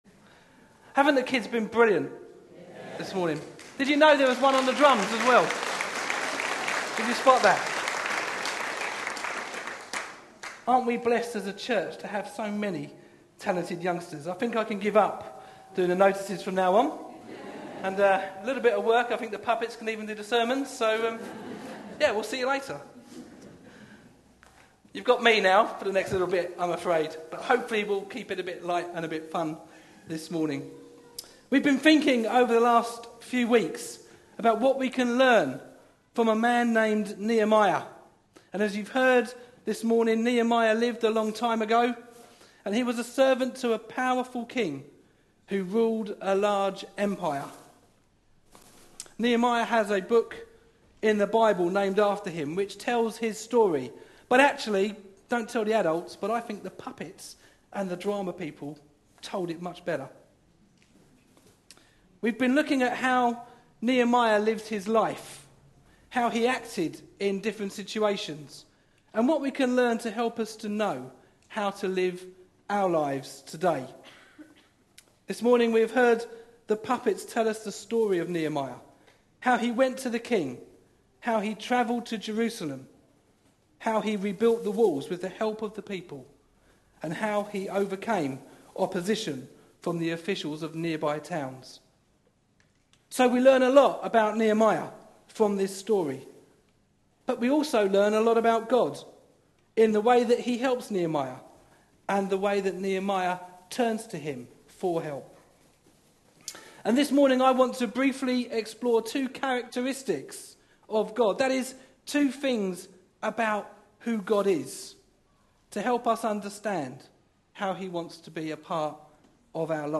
A sermon preached on 13th July, 2014, as part of our Another Brick In The Wall. series.
Nehemiah 9:6-8 Listen online Details This was the talk from a family service celebrating the children of the church, which included presentations by the puppet company and others; no reading, but references to Daniel 2:20-23 (read earlier in the service), Nehemiah 9:6-8, and the Tour de France passing through Chelmsford on July 7th.